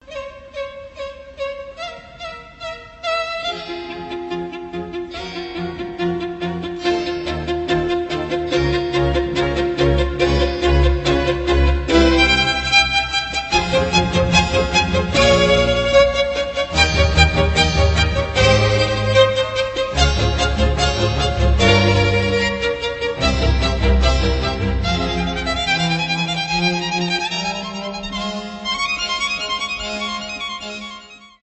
инструментальные , скрипка
классические , без слов